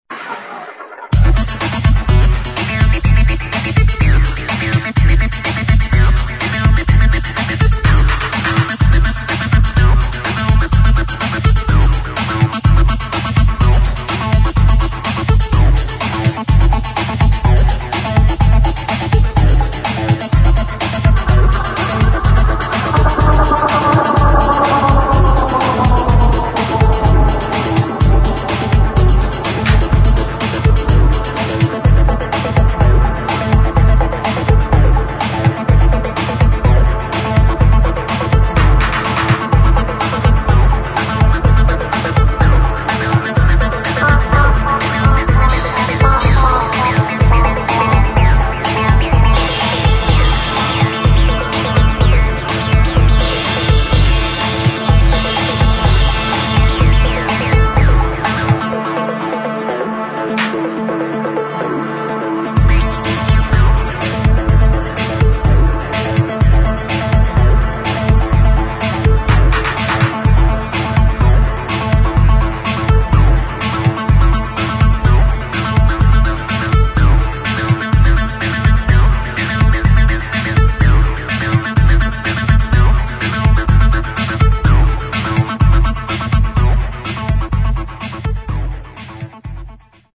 [ HOUSE / TECHNO ]